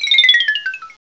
pokeemerald / sound / direct_sound_samples / cries / kricketot.aif
kricketot.aif